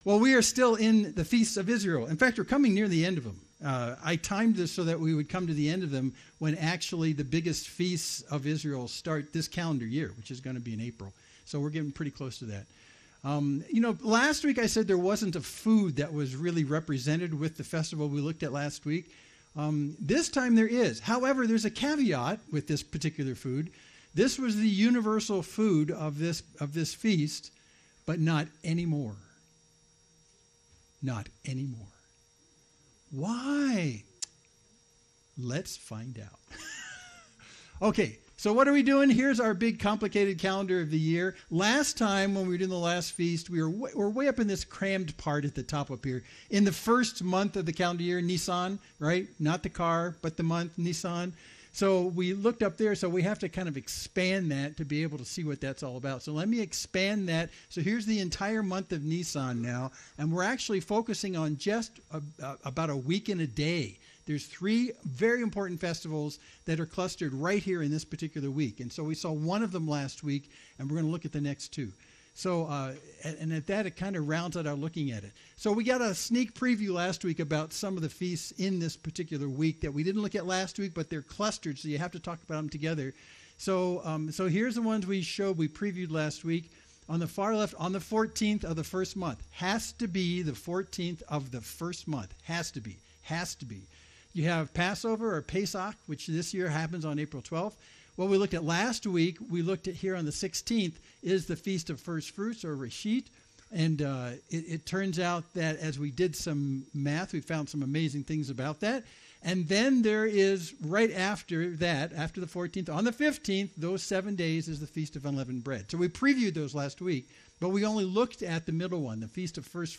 Main Street Church Sermon (17.12 - )